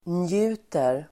Uttal: [nj'u:ter]